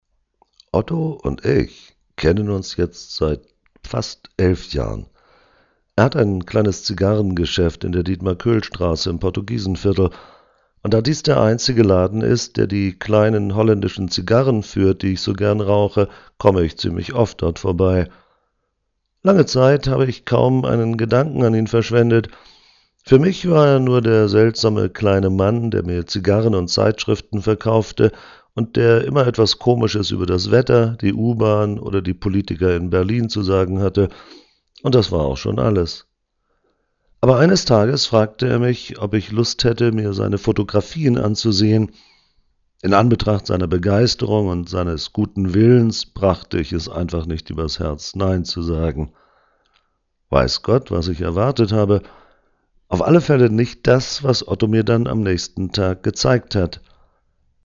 Lesung I